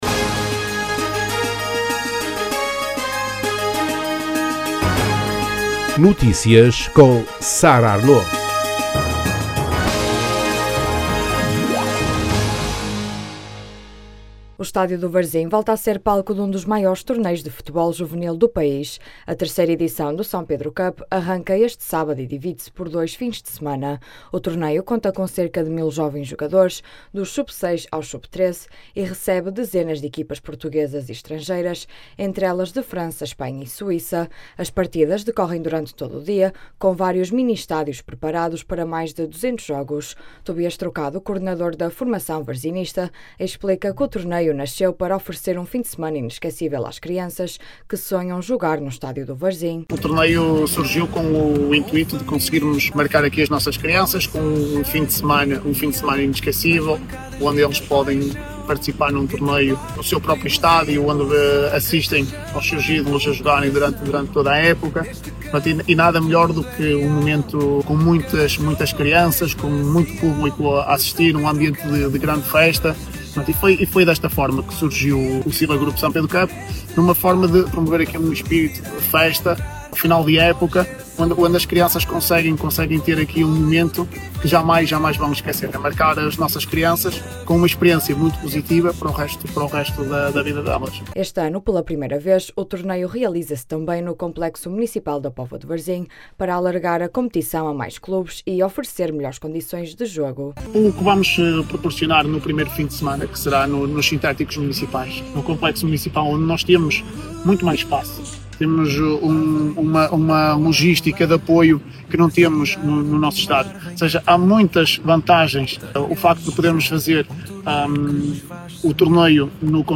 Vaudeville Rendez-Vous volta a Barcelos com sete espetáculos Detalhes Categoria: Notícias Regionais Publicado em sexta-feira, 13 junho 2025 11:16 Escrito por: Redação A Casa da Azenha, em Barcelos, acolheu a apresentação do programa da 11ª edição do Festival Vaudeville Rendez-Vous, o mais influente festival de circo contemporâneo em Portugal.
As declarações podem ser ouvidas na edição local.